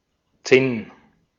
Ääntäminen
Synonyymit gris Ääntäminen France Tuntematon aksentti: IPA: /e.tɛ̃/ Haettu sana löytyi näillä lähdekielillä: ranska Käännös Ääninäyte Substantiivit 1.